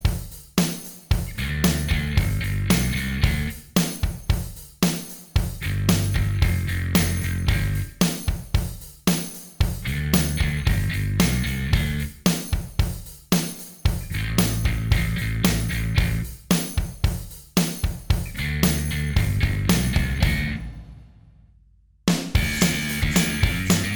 Minus All Guitars Punk 3:11 Buy £1.50